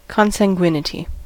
consanguinity: Wikimedia Commons US English Pronunciations
En-us-consanguinity.WAV